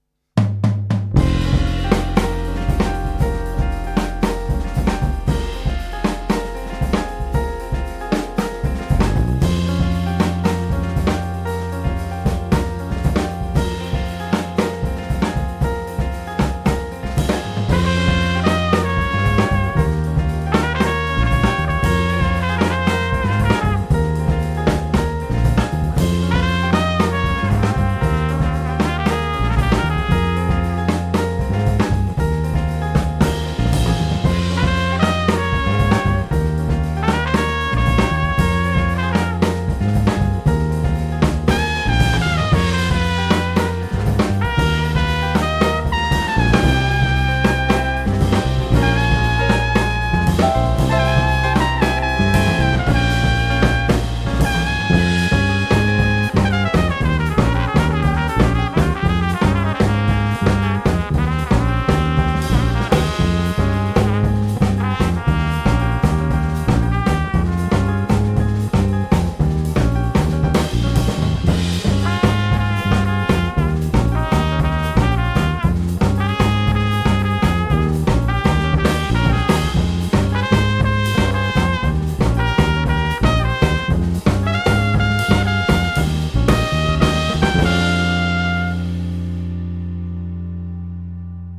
London Jazz Ensemble
• Contemporary/Fusion